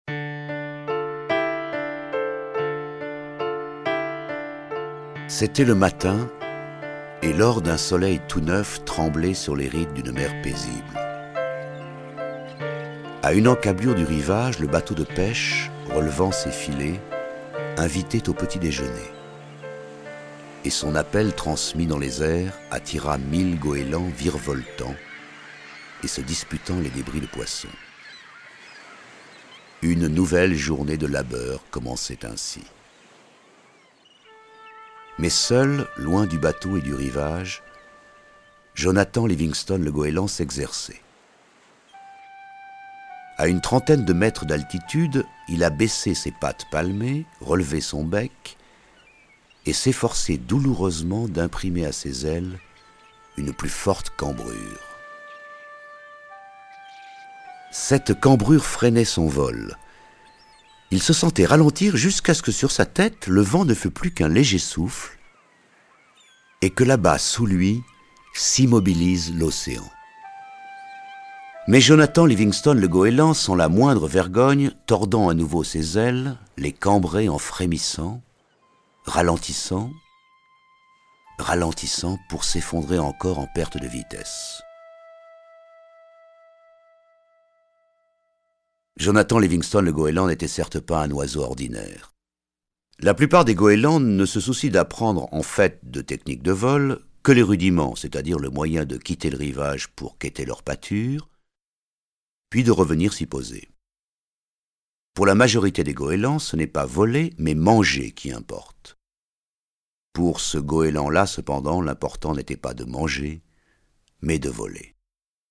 Diffusion distribution ebook et livre audio - Catalogue livres numériques
Cette nouvelle édition livre audio, racontée par Patrice Laffont et mettant en vedette Serge Postigo dans le rôle de Jonathan, ne manquera pas d’émouvoir tous ceux qui, comme Jonathan, sont en quête d’amour et de liberté. Lu par Patrice Laffont Durée : 58min 14 , 40 € Ce livre est accessible aux handicaps Voir les informations d'accessibilité